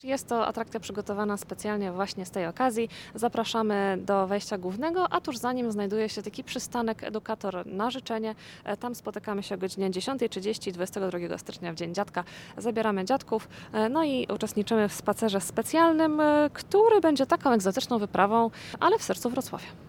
– Warto zabrać swoich dziadków na spacer po zoo –